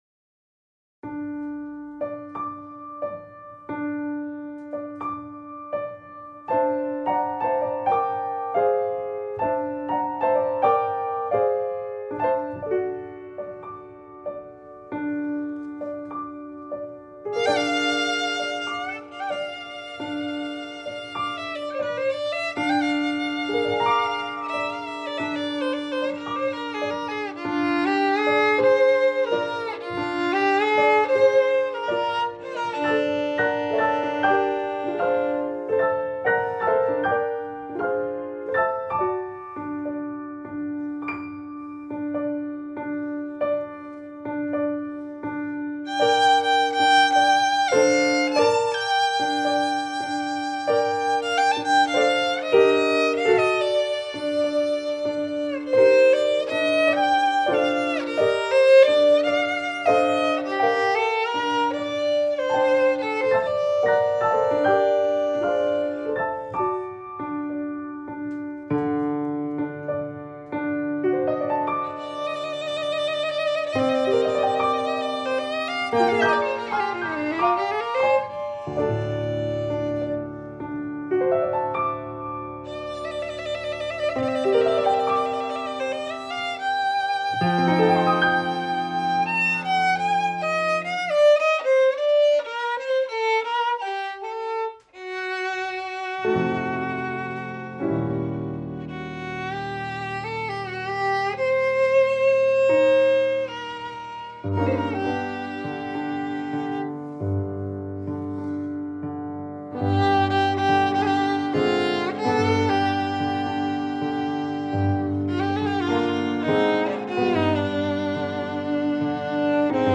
pianist